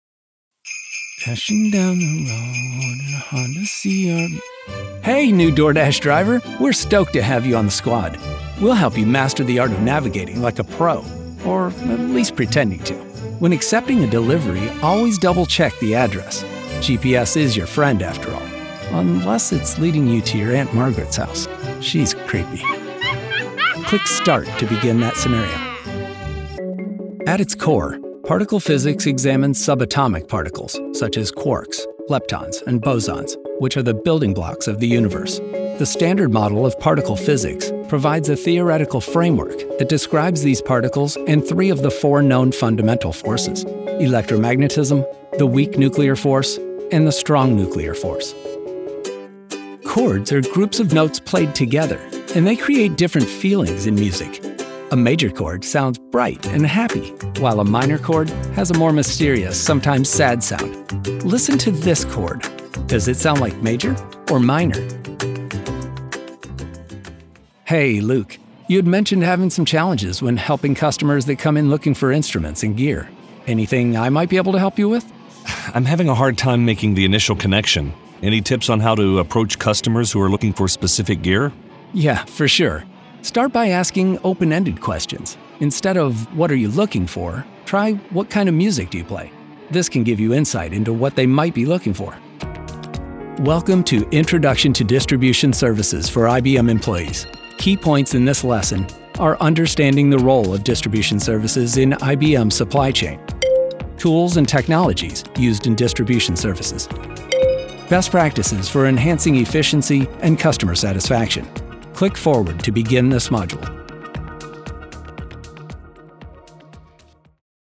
American Male Voice Talent - Friendly, Relatable, Confident, Authentic, Real
Middle Aged